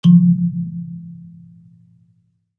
kalimba_bass-F2-ff.wav